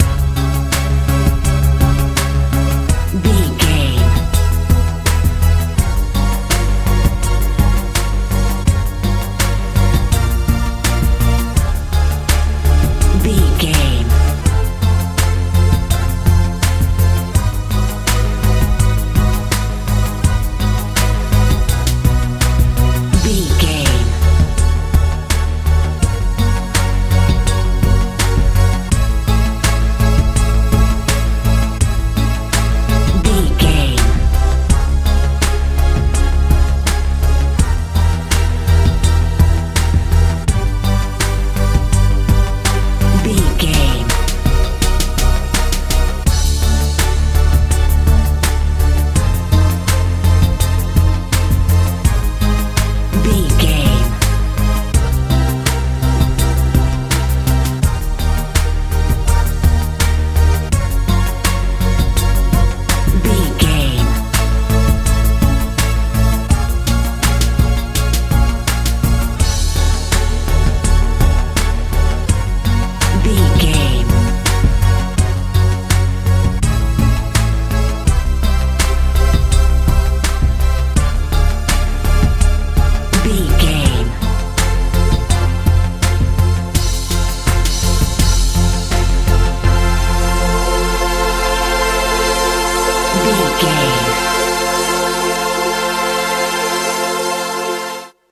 modern dance feel
Ionian/Major
disturbing
dramatic
80s
90s